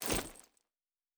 pgs/Assets/Audio/Fantasy Interface Sounds/Bag 15.wav at master
Bag 15.wav